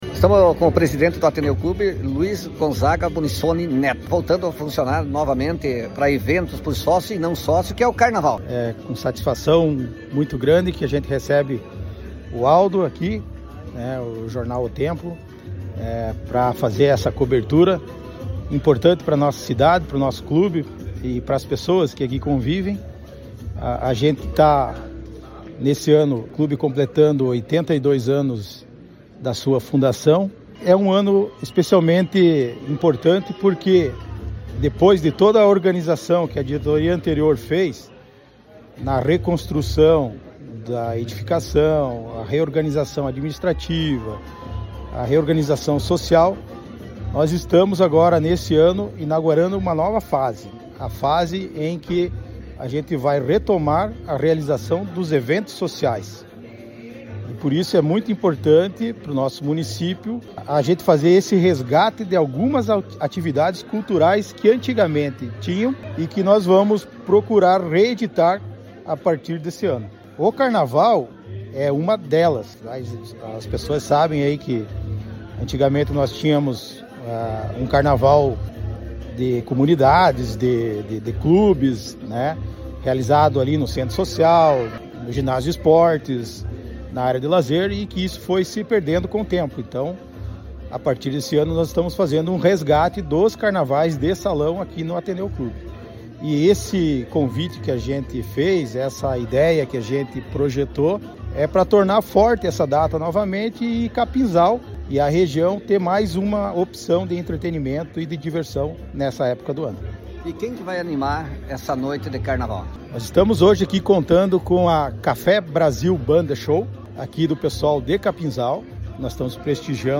Em entrevista ao jornal O TEMPO – Jornal de Fato